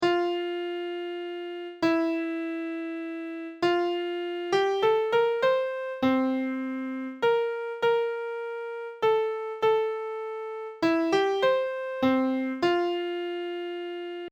Canon for Equal Voices